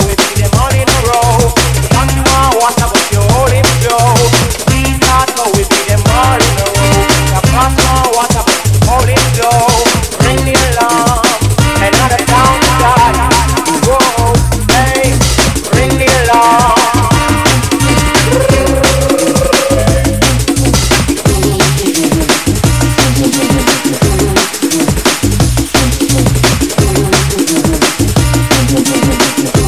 TOP >Vinyl >Drum & Bass / Jungle
TOP > Vocal Track
TOP > Jump Up / Drum Step